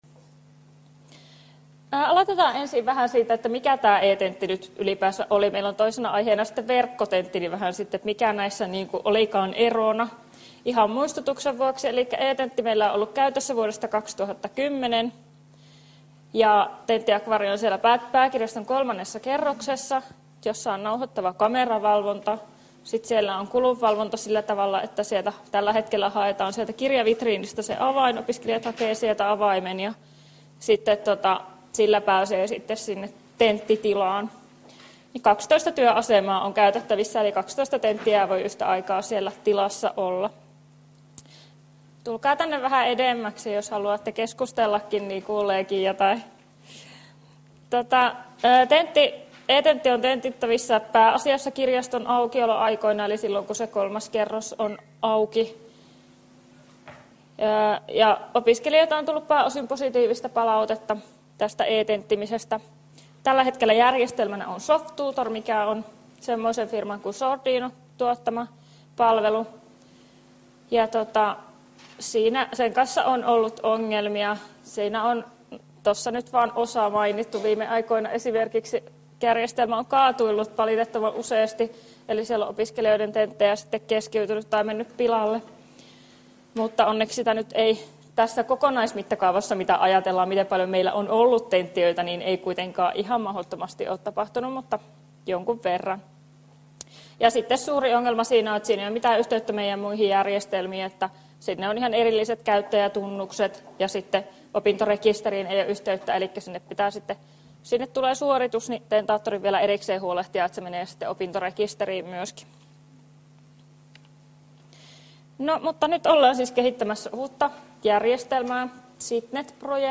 ITP-seminaari 4.4.2014. Kesto 28 min.